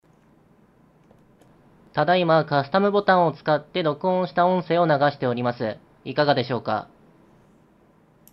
カスタムボタンの音声
AmpliGame SC3のカスタムボタンで録音した音声です。録音なので、音質は悪いですね。